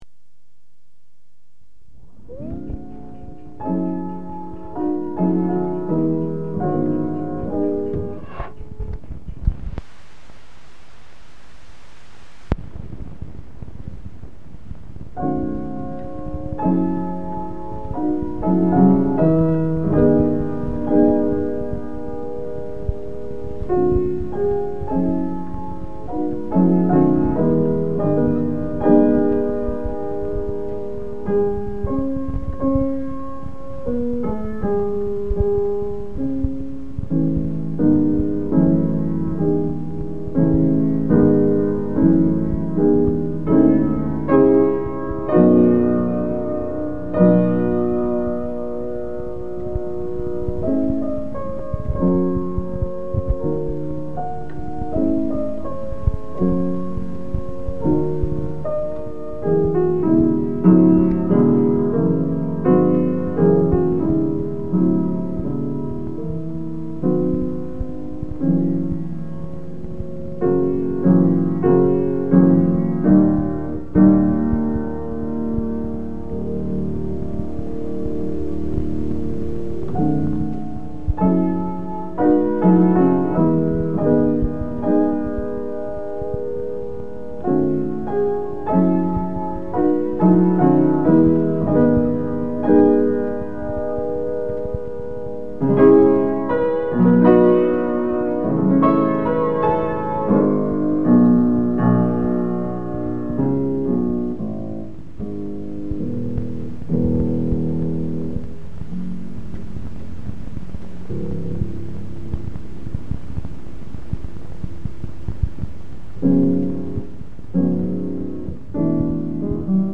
Piano being played
"Piano being played": recording of the second movement ('Sarabande') of solo piano suite 'Pour le piano' (L.95) by French composer Claude Debussy, performed by an unnamed pianist.
reel-to-reel tape recordings of music and soundscapes